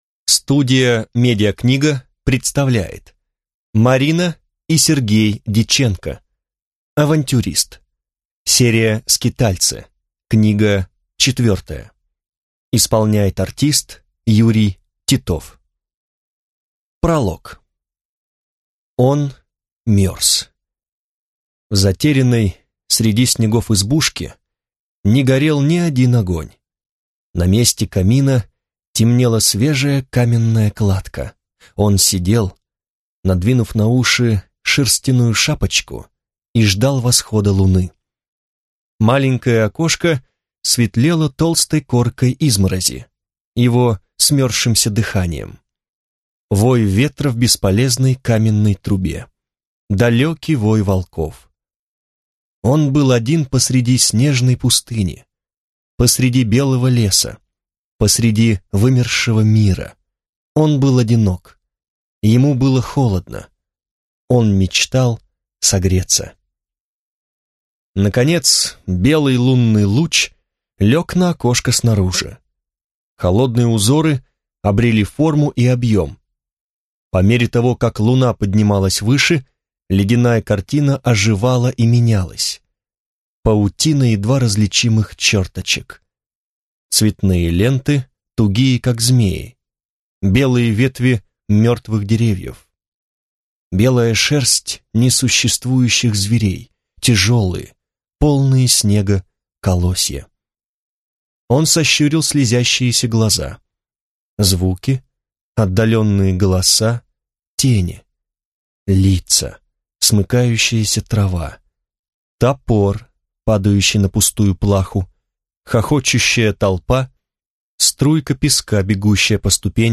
Аудиокнига Авантюрист | Библиотека аудиокниг